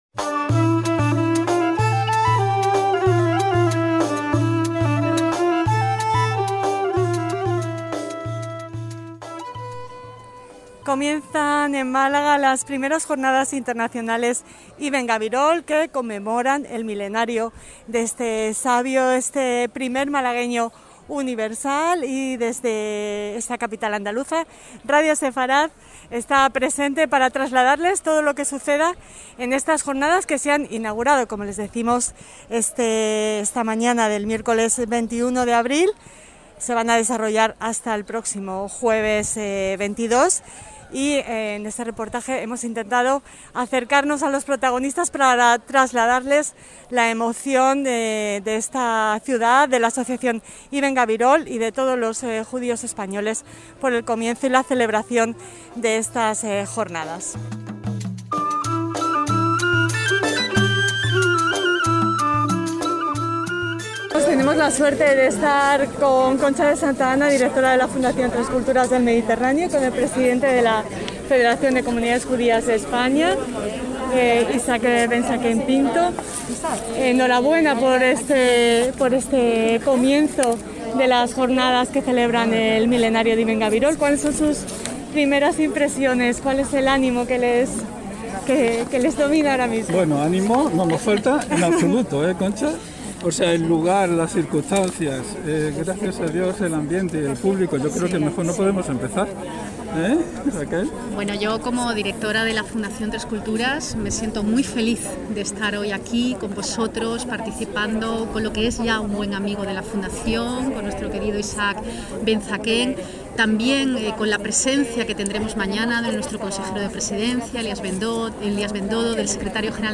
Estuvimos presentes desde el primer minuto (incluso algunos antes) para charlar con las personalidades allí reunidas: